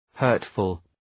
Προφορά
{‘hɜ:rtfəl}